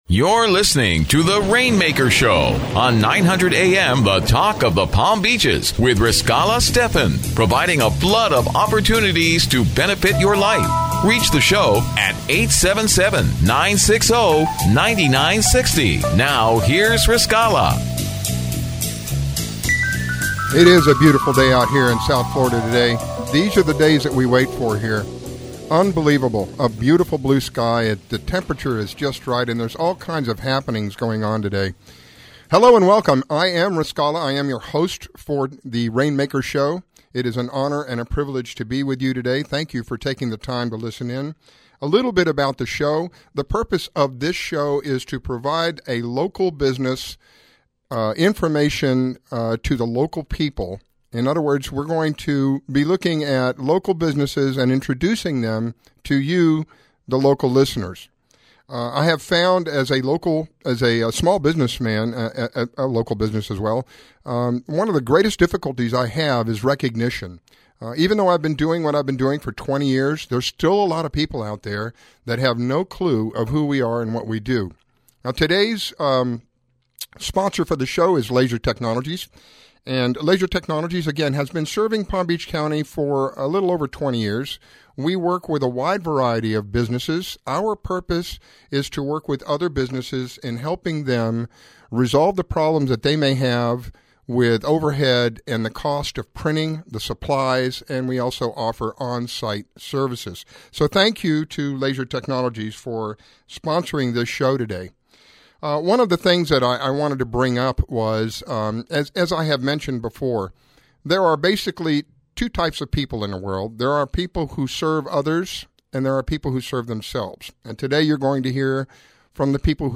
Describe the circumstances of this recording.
Call-ins are encouraged!